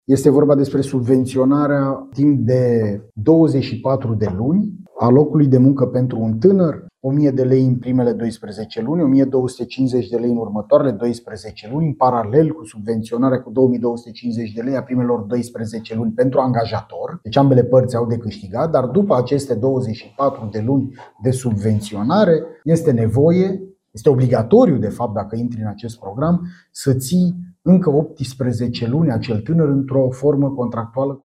Ministrul muncii, Florin Manole într-o conferință de presă la Arad: